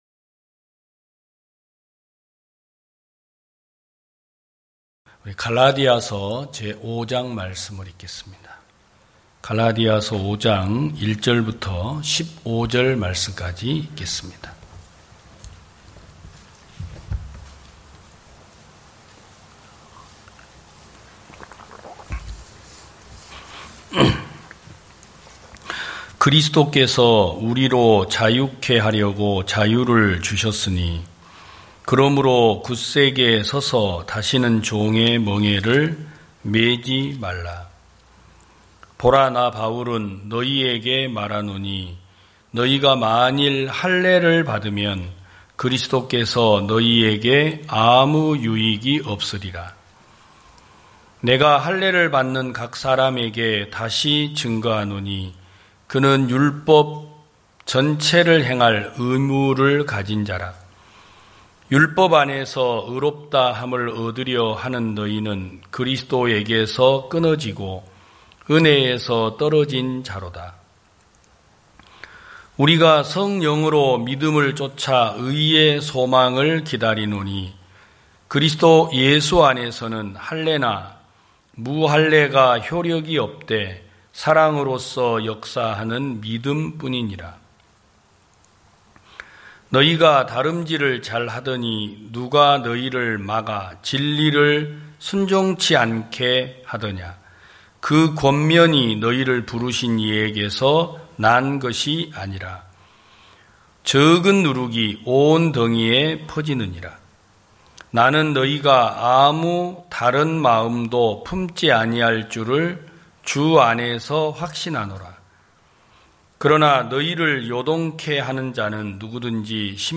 2021년 08월 15일 기쁜소식부산대연교회 주일오전예배
성도들이 모두 교회에 모여 말씀을 듣는 주일 예배의 설교는, 한 주간 우리 마음을 채웠던 생각을 내려두고 하나님의 말씀으로 가득 채우는 시간입니다.